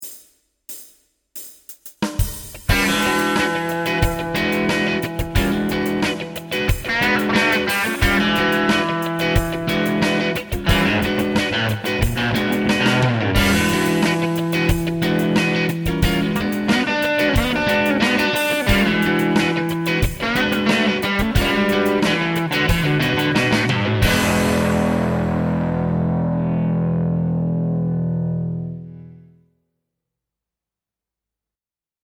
プリアンプ(LINE6 POD2)
HDR での処理は、レベル合わせのためのノーマライズと、軽いリバーブのみ。
R3   Vanzandt Vintage N, B55 Center: Neck+Bridge: Crunch
Left: Bridge: Lead
Right: Neck: Lead